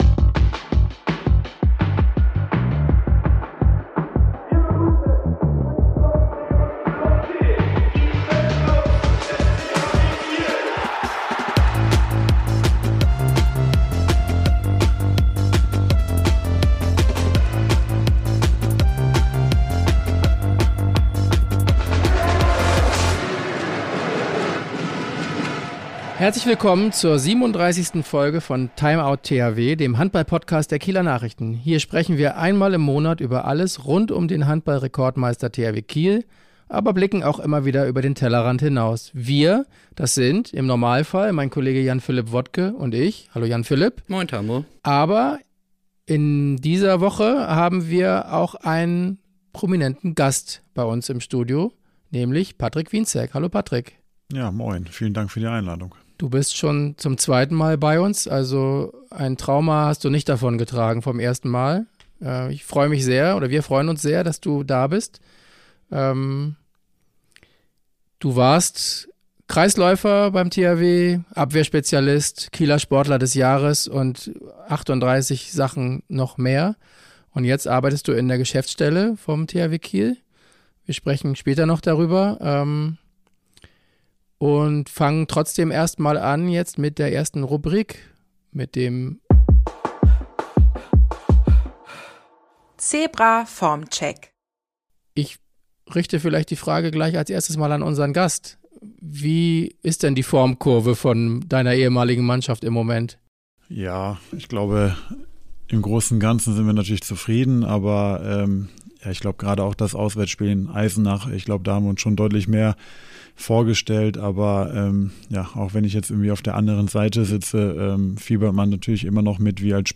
Der Ex-Kreisläufer des Rekordmeisters spricht im Podcast-Studio über (böse) Träume, seine neue Rolle im Verein und kulinarische Weihnachtspläne.